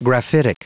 Prononciation du mot graphitic en anglais (fichier audio)
Prononciation du mot : graphitic